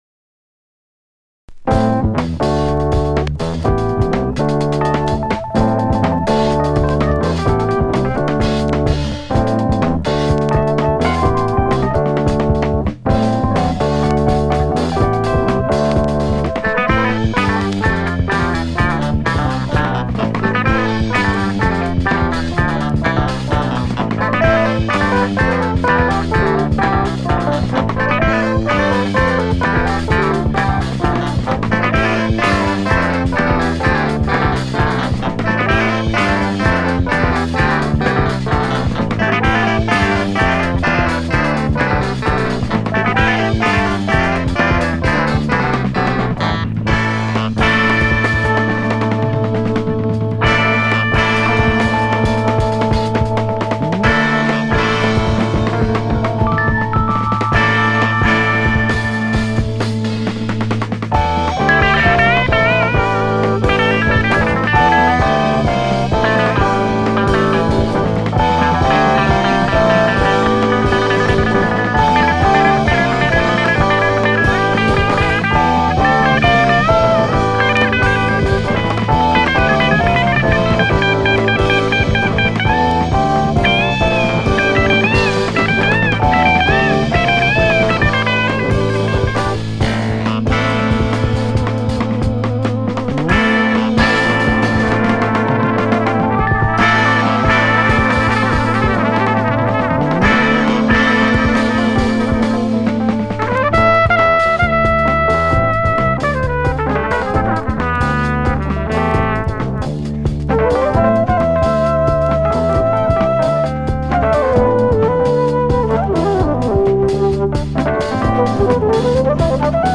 はタイトル通り宇宙を感じさせる、各楽器のプレイも鮮やかなスリリングな魅力溢れるジャズロック。